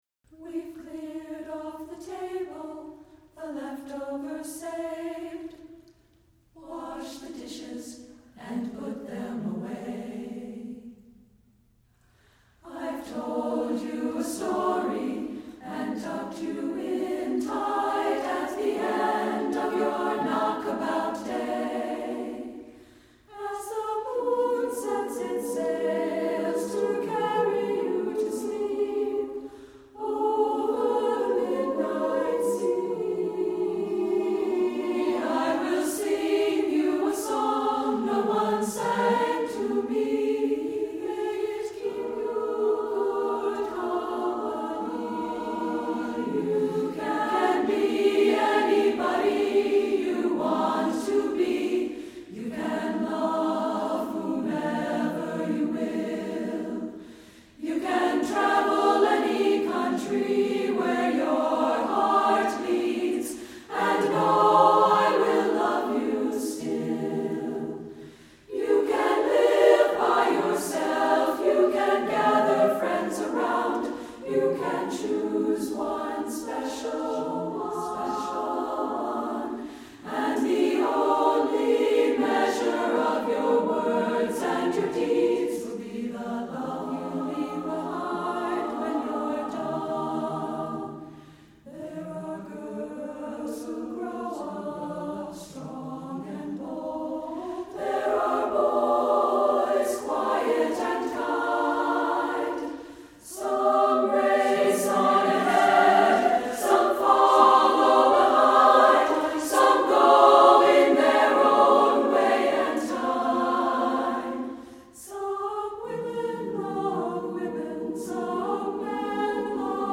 Version for SSAA